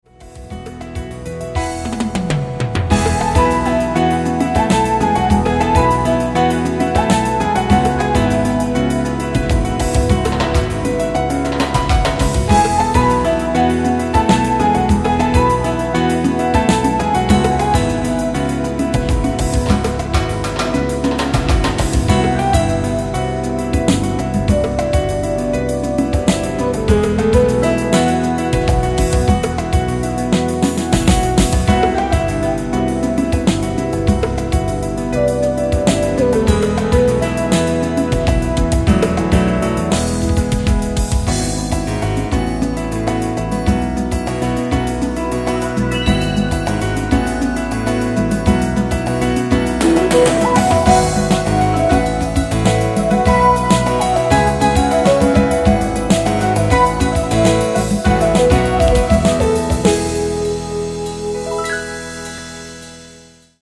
Jazz-progressif.